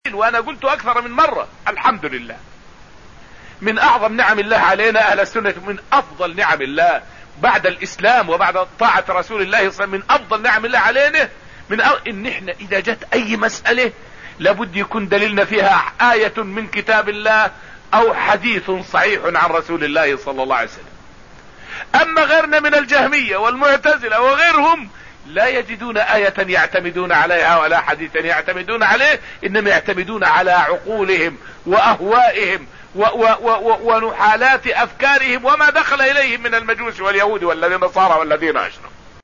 فائدة من الدرس التاسع من دروس تفسير سورة الرحمن والتي ألقيت في المسجد النبوي الشريف حول صحة وكثرة أدلة أهل السنة على عقيدتهم.